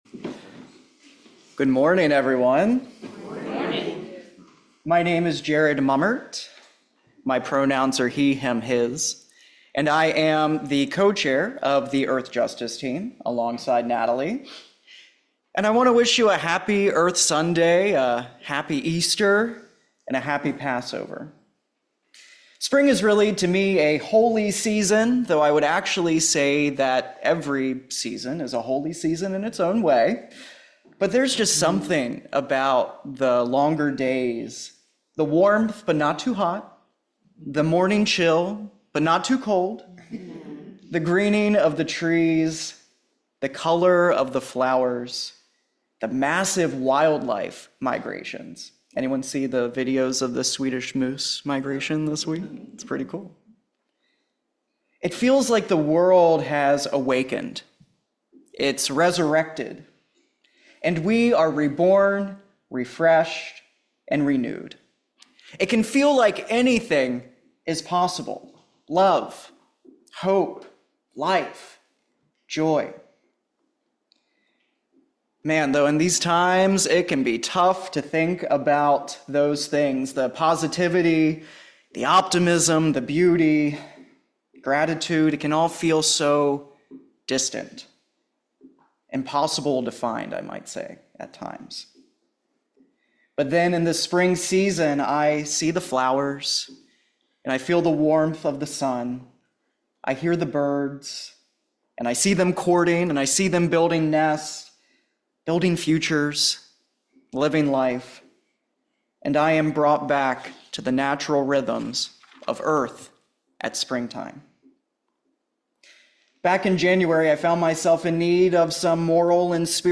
In !Audio Sermon, Earth Justice, Nature, Resilience